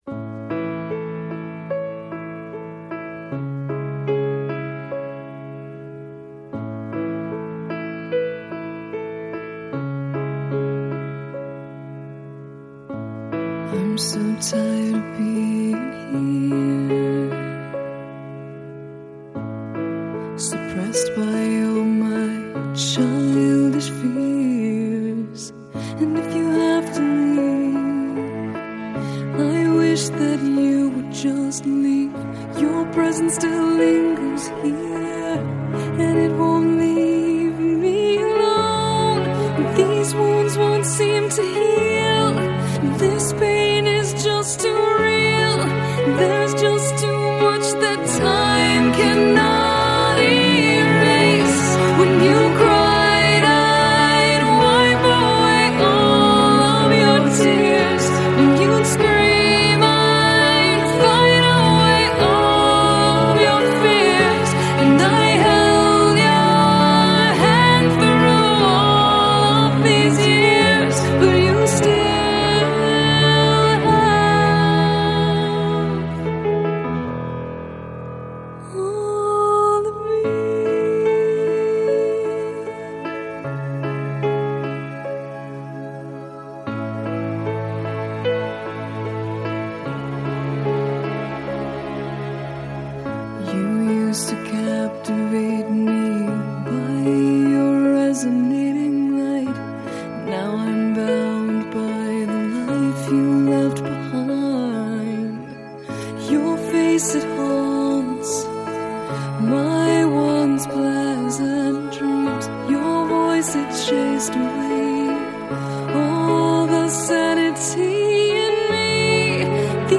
Жанр: Alternative, Gothic Rock